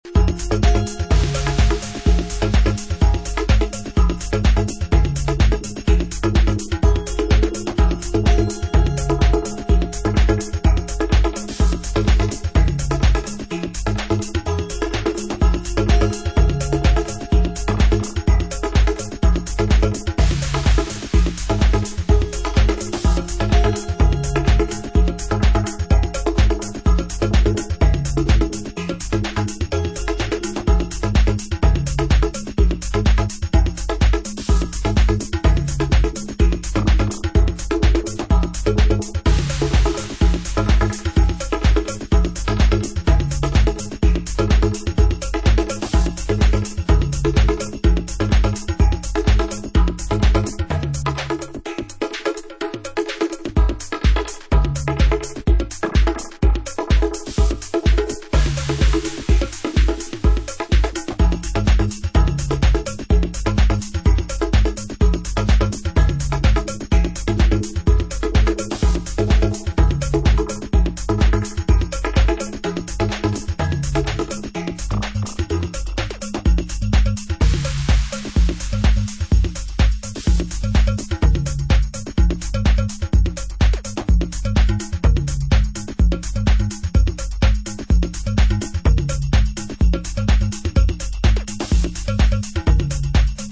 Genre: Minimal Techno